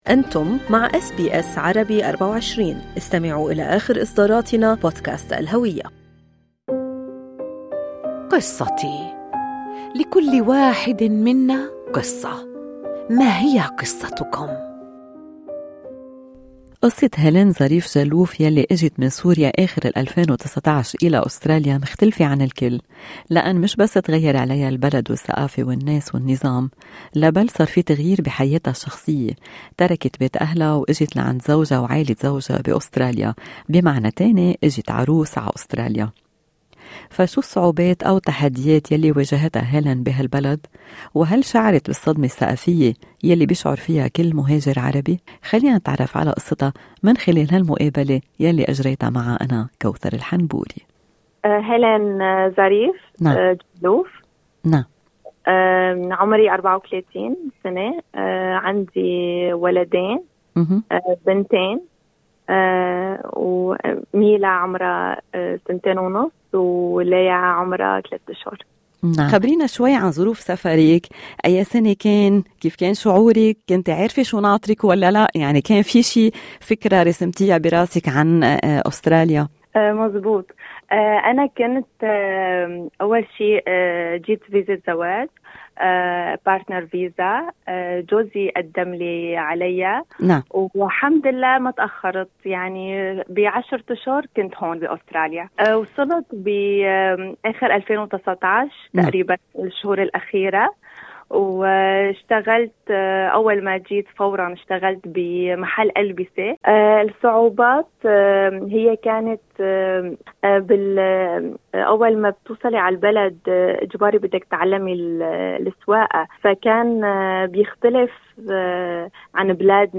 تعرفوا معنا الى قصتها في التقرير الصوتي المرفق بالصورة أعلاه.